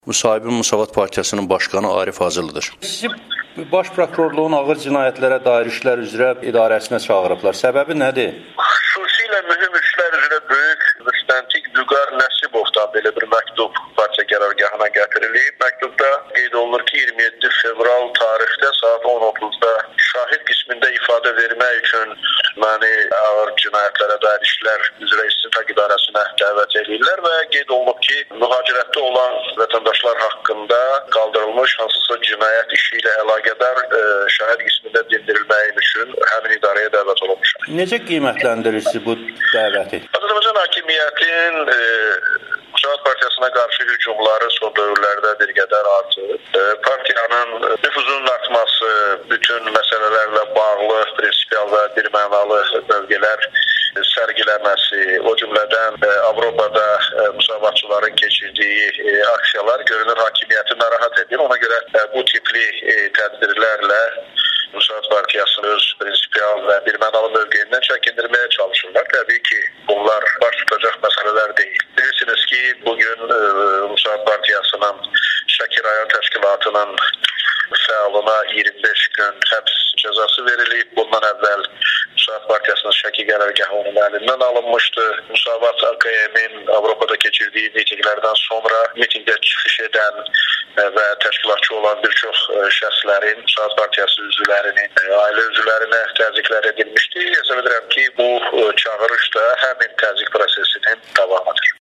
müsahibə